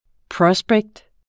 Udtale [ ˈpɹʌsbεgd ]